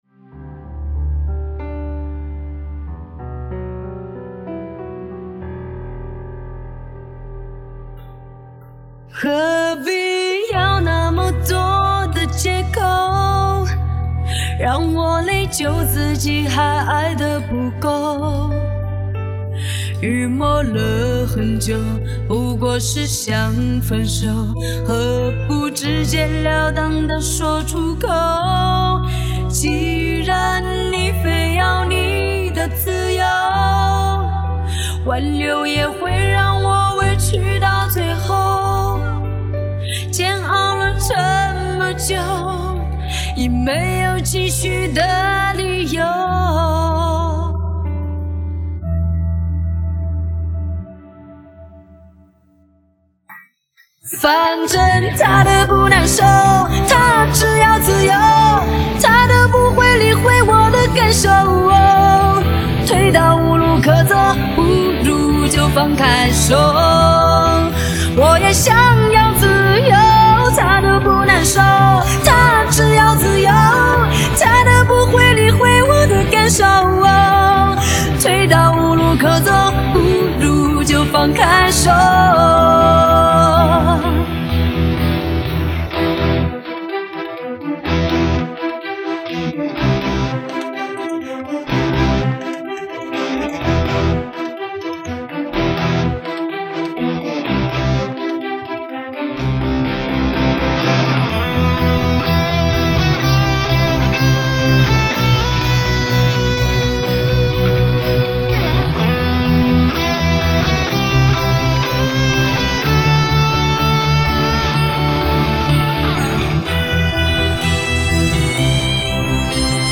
华语